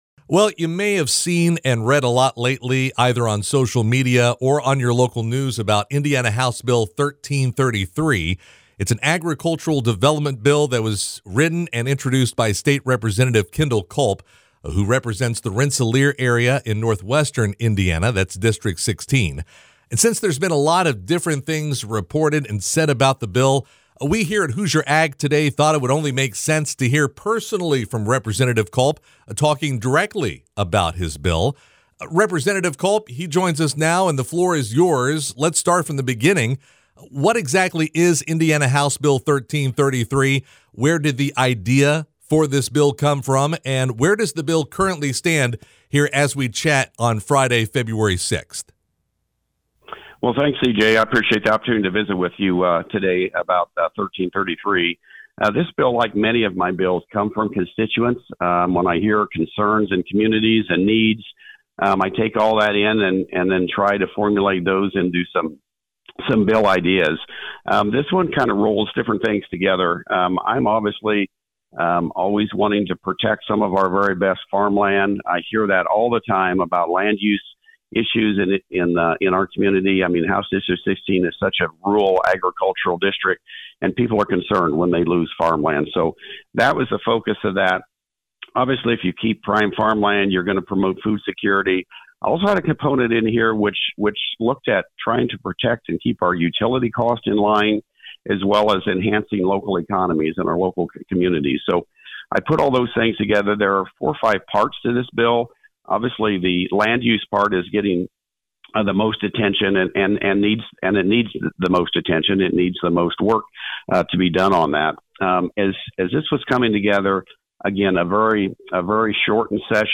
State Rep. Kendell Culp (R-Rensselaer), who wrote the bill, tells Hoosier Ag Today he isn’t happy with it either.
cj-interview-kendell-culp-house-bill-1333.mp3